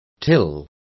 Complete with pronunciation of the translation of tills.